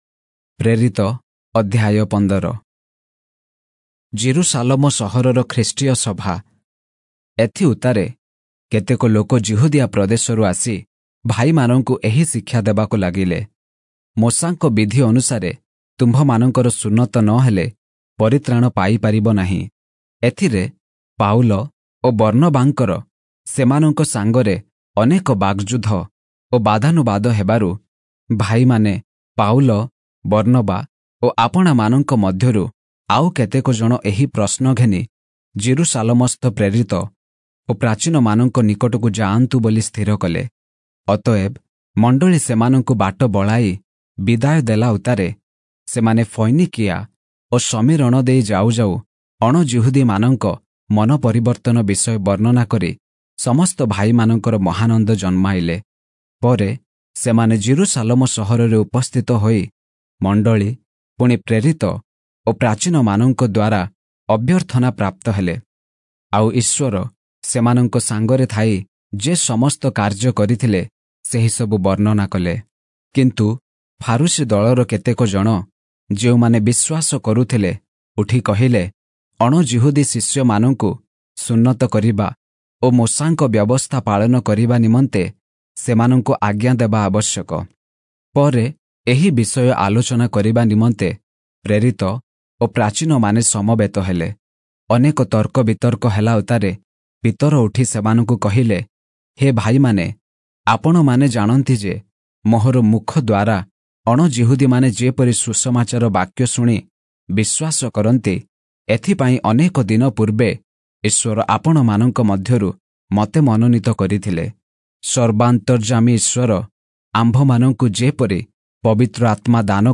Oriya Audio Bible - Acts 11 in Irvor bible version